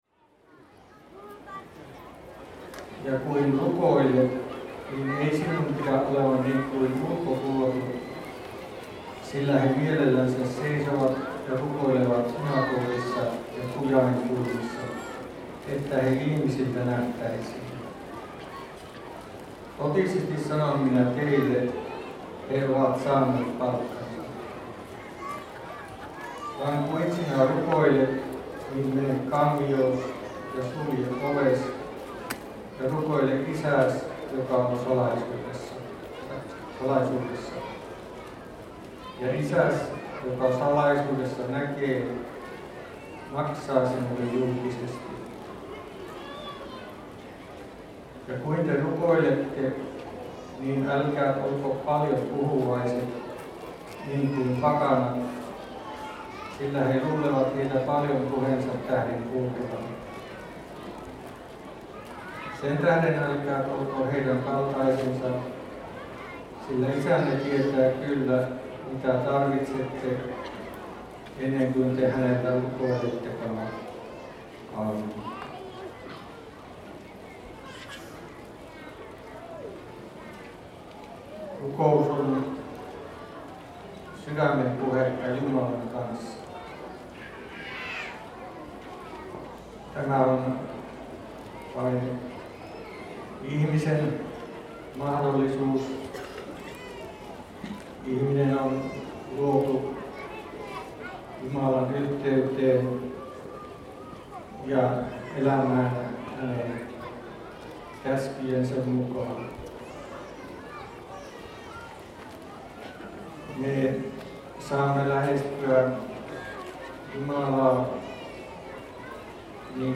Summer services IV: Reading of the Gospel in the Main Tent
Summer services is the biggest spiritual meeting in Finland and one of the biggest summer festivals gathering somewhat 80000 people every year.
• Soundscape